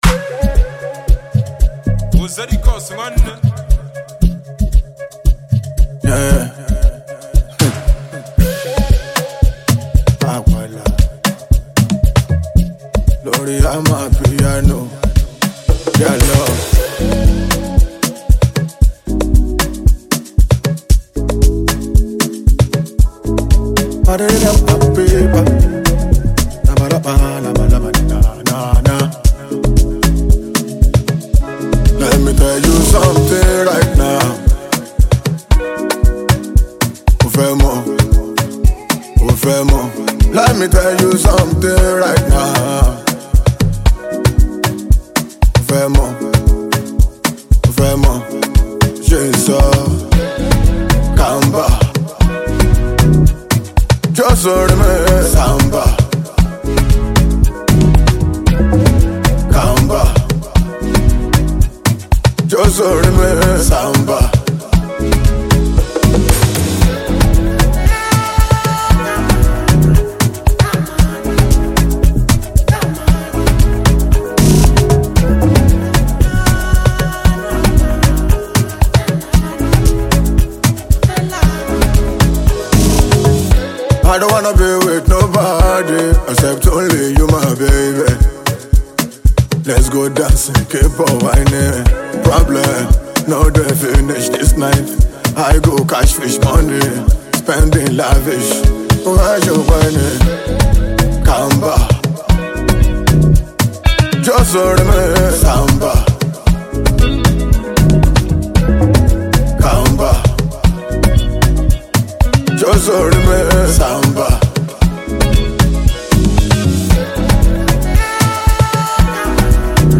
amapiano song